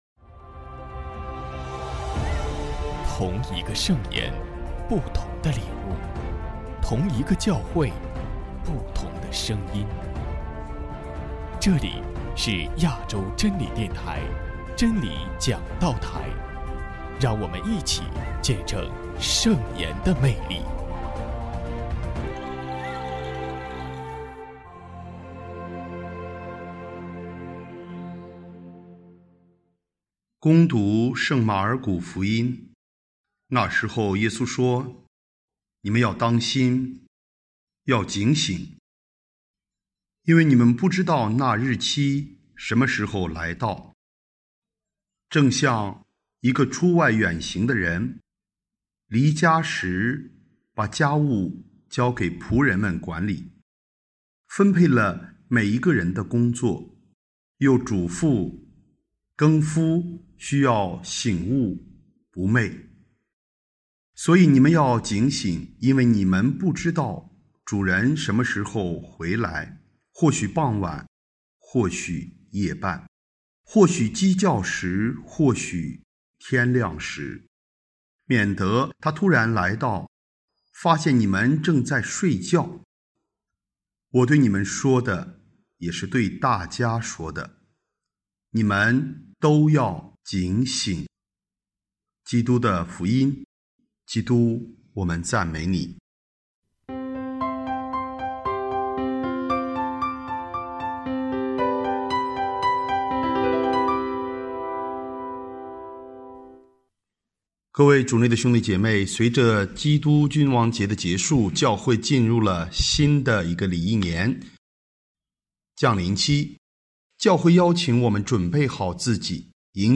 【真理讲道台】|将临期的意义——乙年将临期第一主日